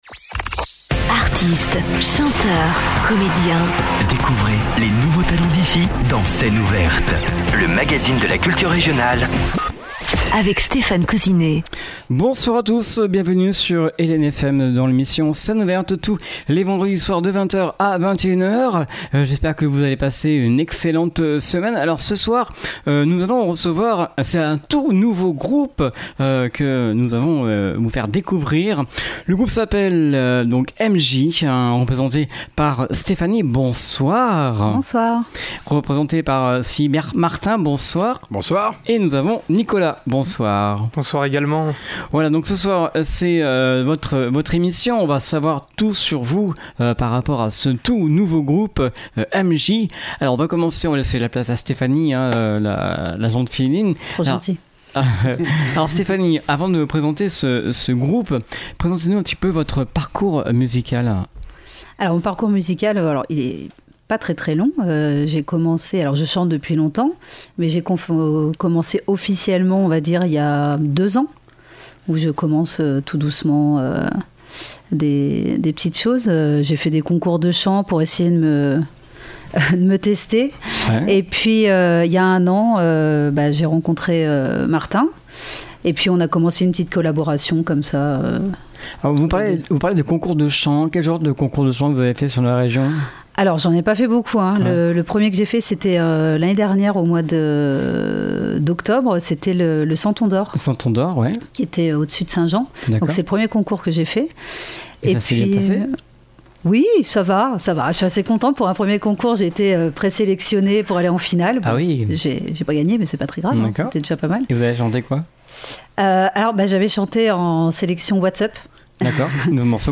guitare sèche et chant
guitare électrique